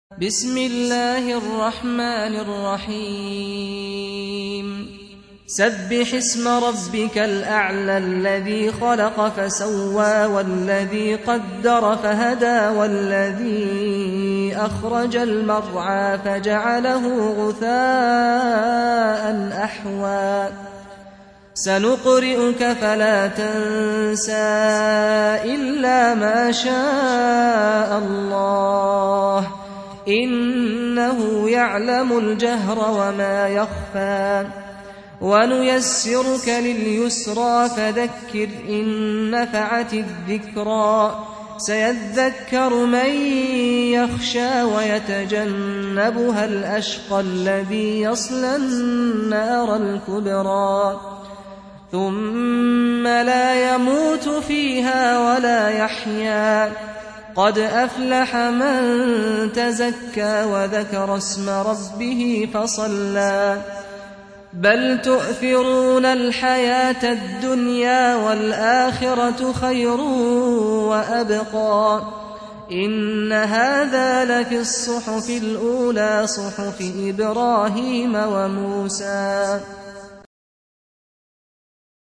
87. Surah Al-A'l� سورة الأعلى Audio Quran Tarteel Recitation
Surah Repeating تكرار السورة Download Surah حمّل السورة Reciting Murattalah Audio for 87.